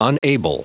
Prononciation du mot unable en anglais (fichier audio)
Prononciation du mot : unable